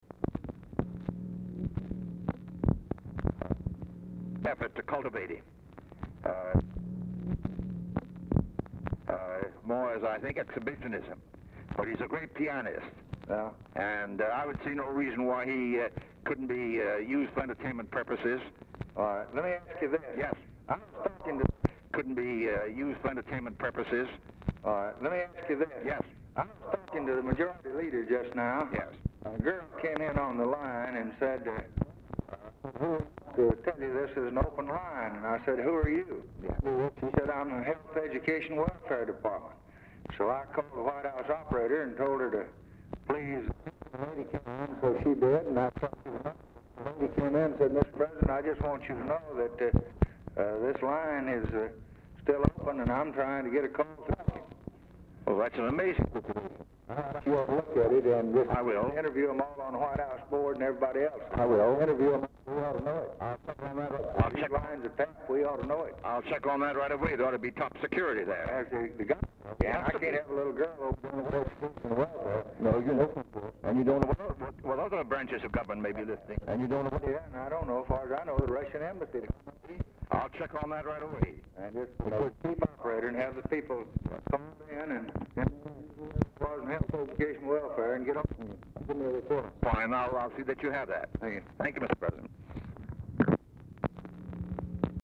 Telephone conversation
RECORDING STARTS AFTER CONVERSATION HAS BEGUN; POOR SOUND QUALITY
Format Dictation belt